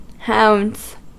Ääntäminen
Ääntäminen US : IPA : [ˈhaʊndz] Haettu sana löytyi näillä lähdekielillä: englanti Käännöksiä ei löytynyt valitulle kohdekielelle. Hounds on sanan hound monikko.